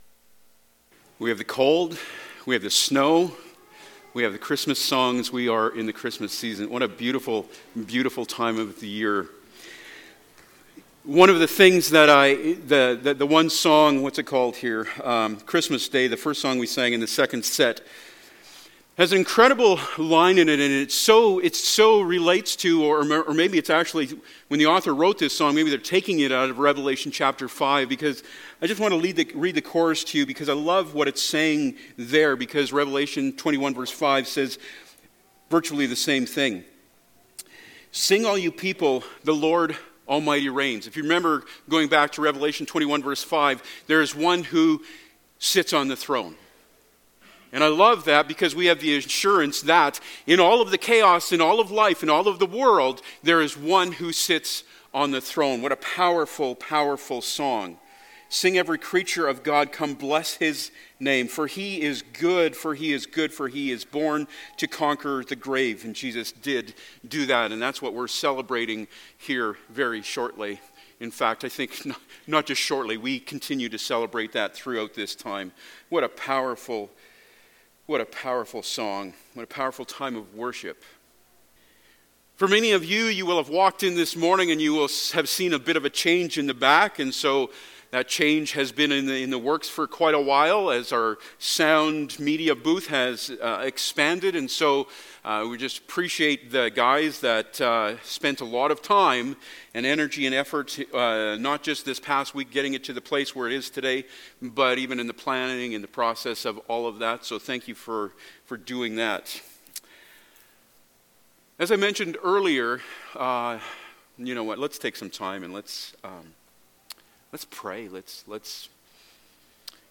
Passage: Revelation 21:1-4 Service Type: Sunday Morning